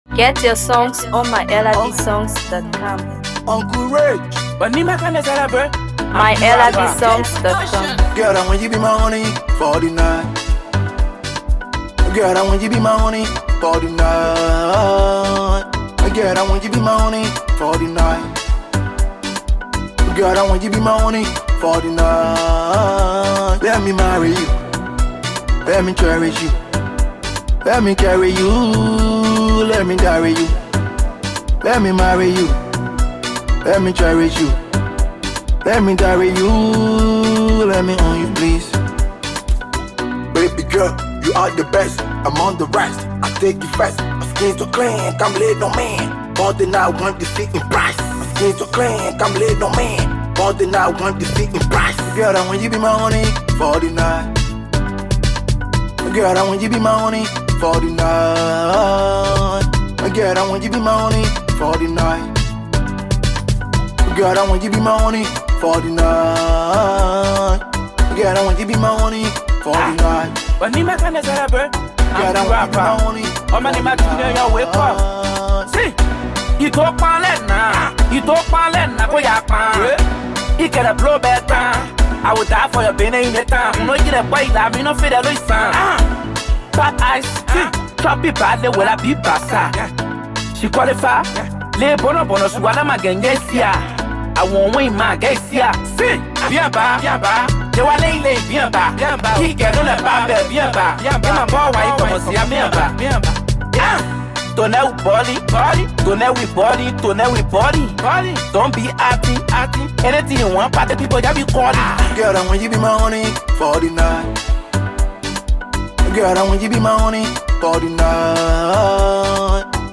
Afro PopMusic